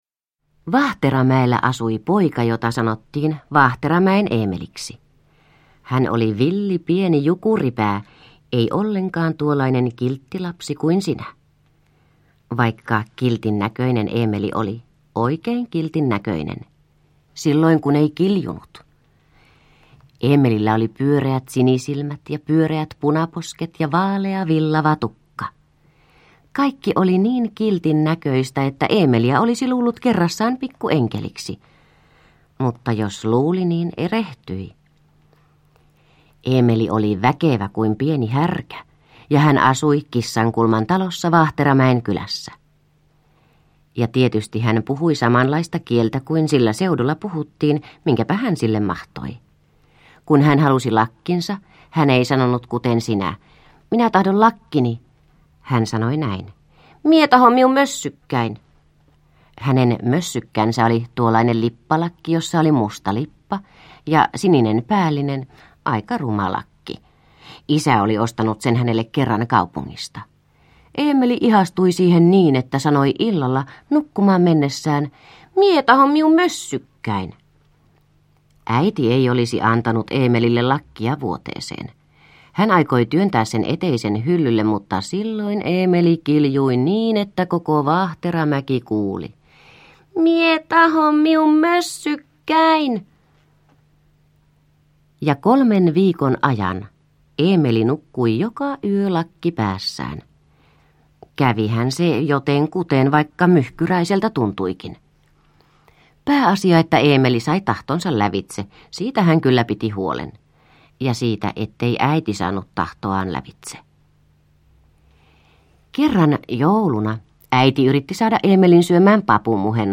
Vaahteramäen Eemeli – Ljudbok – Laddas ner